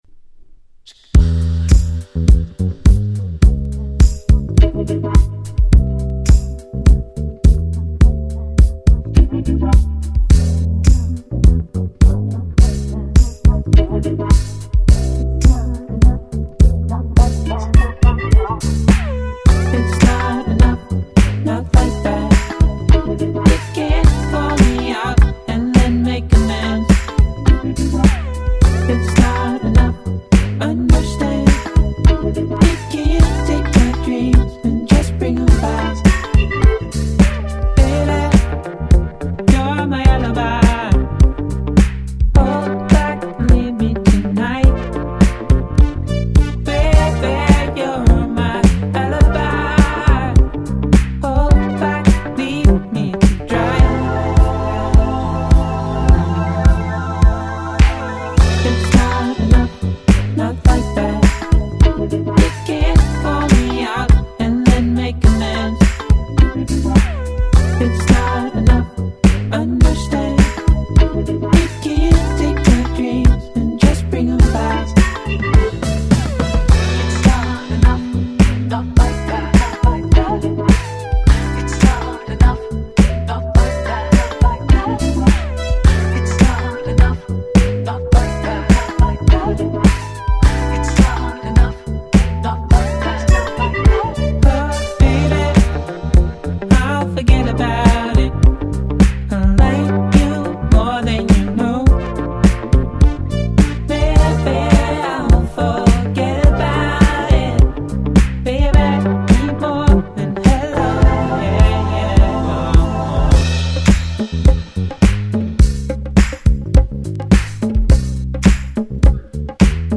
19' Nice UK R&B/Soul !!
最高に心地良い1曲です！